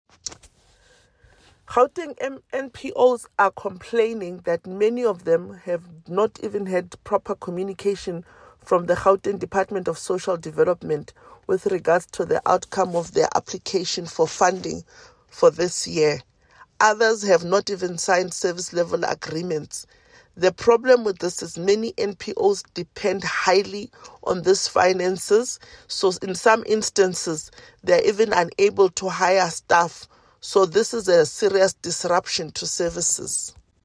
soundbite by Refiloe Nt’sekhe MPL.